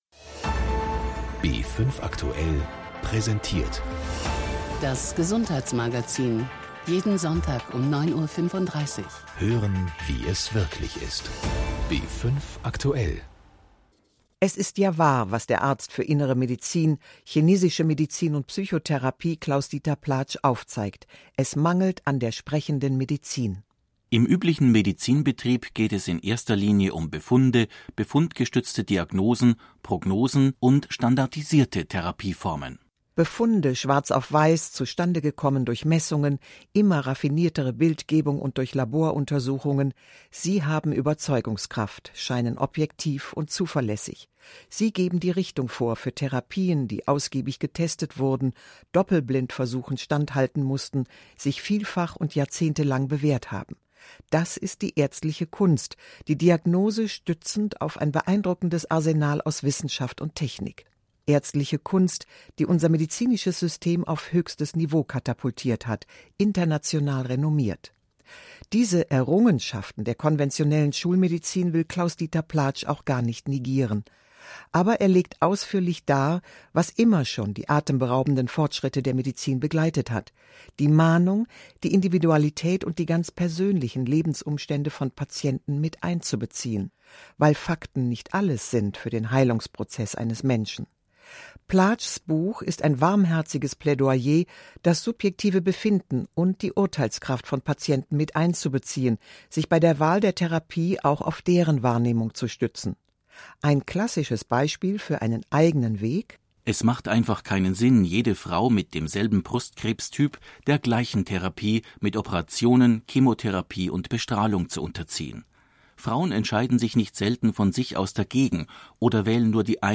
Das heilende Feld - Buchbesprechung im Gesundheitsmagazin